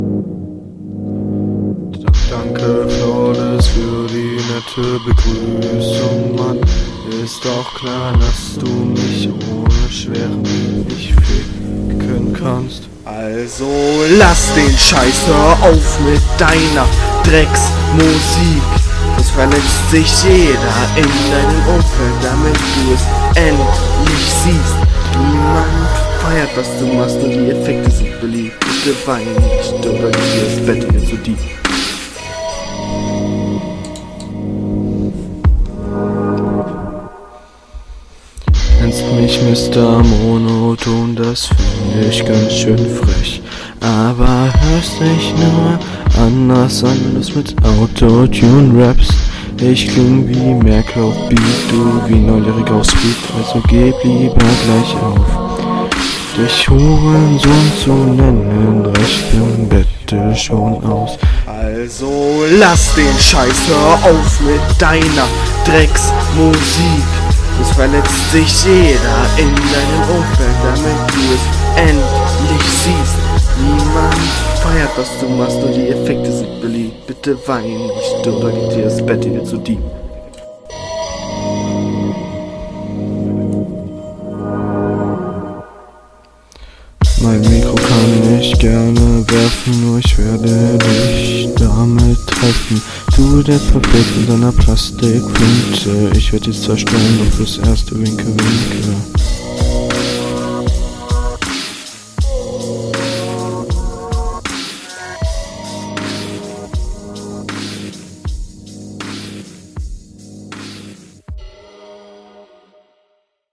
Größter Kritikpunkt der Runde: Die Mische und die Soundqualität vom mic.
Soundqualität ist schon nicht so geil.